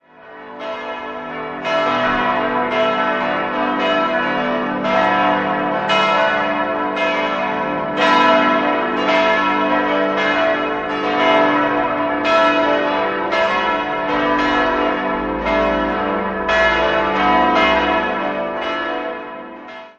5-stimmiges Geläute: h°-d'-e'-fis'-a' Die große Glocke wurde 1960, die Glocken 2, 3 und 5 im Jahr 1953 von Rudolf Perner in Passau gegossen.